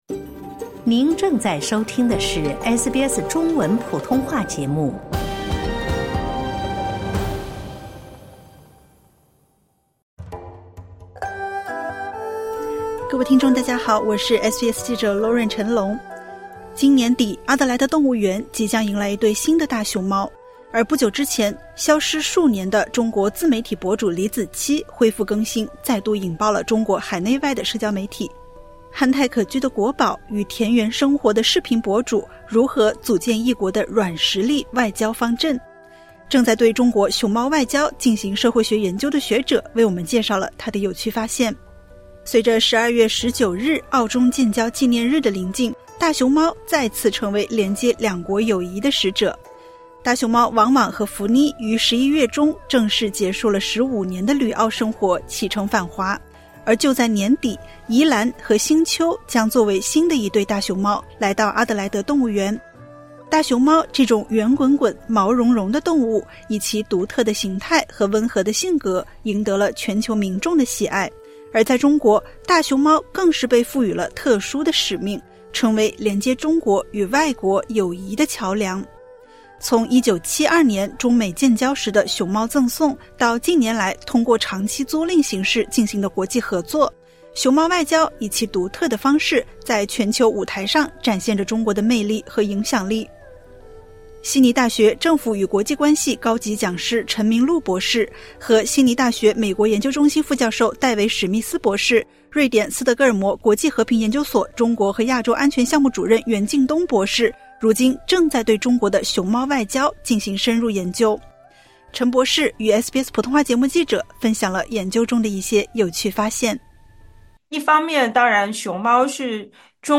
憨态可掬的“国宝”与田园生活的视频博主，如何组建一国的“软实力”外交方阵？正在对中国“熊猫外交”进行社会学研究的学者为我们介绍她的有趣发现。点击 ▶ 收听采访。